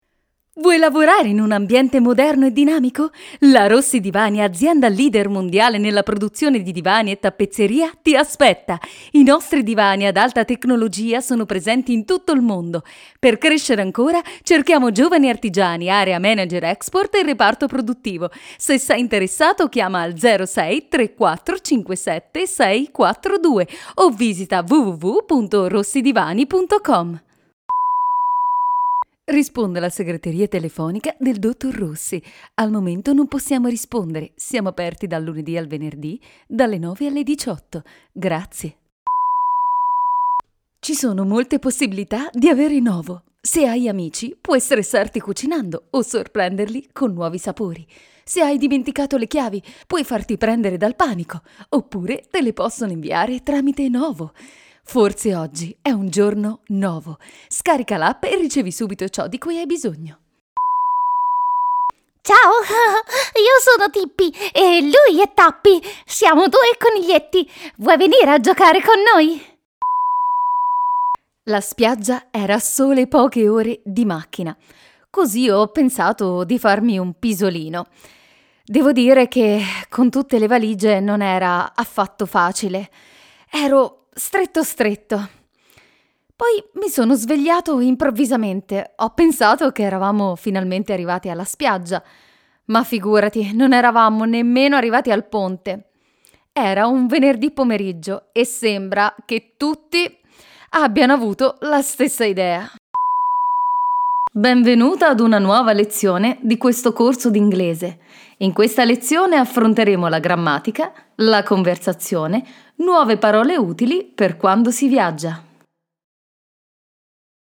Young, Engaging, Deep, Cartoon, Narrator
Italian Demo
Young Adult